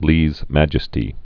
(lēz măjĭ-stē)